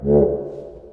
描述：普蒂普是一种打击乐器，用于那不勒斯的民间音乐，一般来说，用于意大利南部大部分地区的民间音乐。(另一个名字是"caccavella"。)普蒂普这个名字是乐器演奏时发出的"打嗝"的拟声词。该乐器由一个横跨共鸣室的薄膜组成，就像一个鼓。然而，膜不是被卡住的，而是用一个手柄在腔内有节奏地压缩空气。然后，空气从将膜固定在乐器的粘土或金属主体上的不完全密封处喷出，声音很大。
Tag: caccavella 粘土 民族 民俗 那不勒斯 打击乐器 putip 摩擦